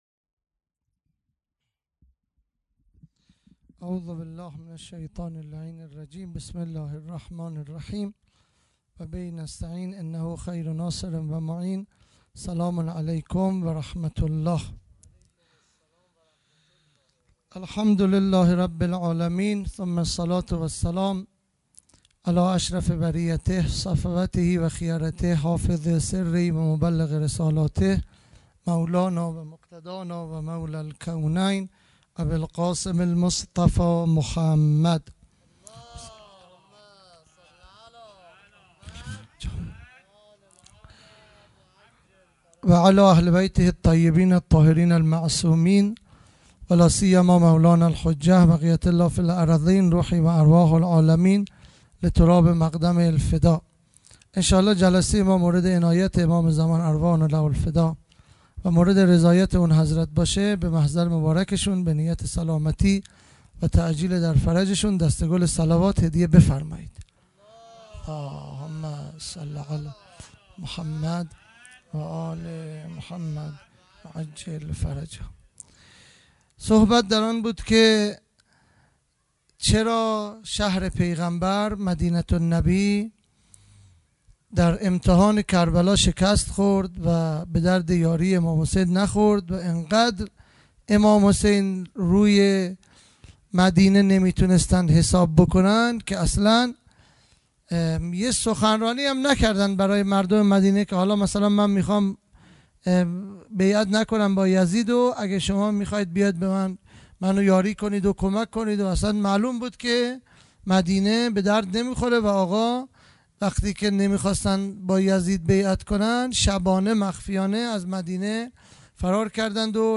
خیمه گاه - هیئت بچه های فاطمه (س) - سخنرانی | زمینه های اجتماعی قیام کربلا
دهه اول محرم الحرام ۱۴۴٢ | شب سوم